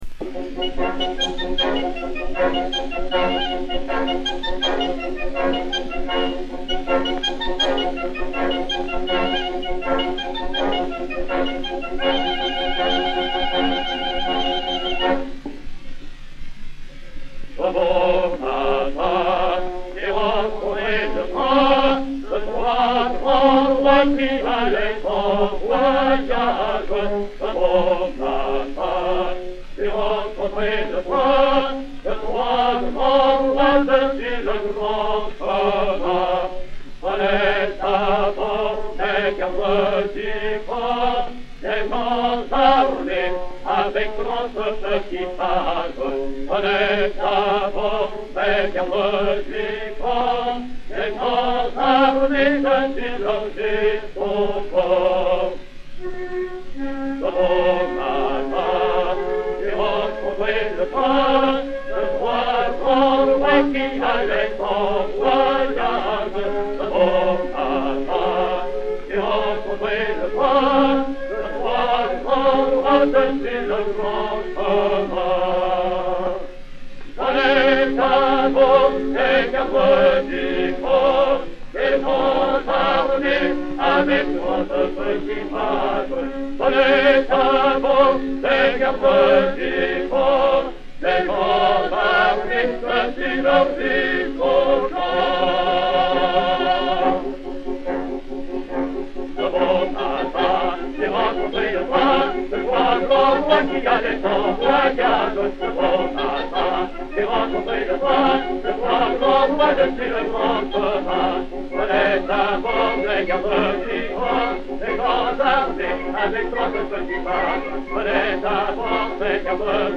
Orchestre
Pathé saphir 90 tours n° 777, réédité sur 80 tours n° 2508, enr. en 1910